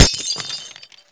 glass3.wav